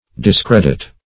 Discredit \Dis*cred"it\, v. t. [imp.